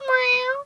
cat_2_meow_06.wav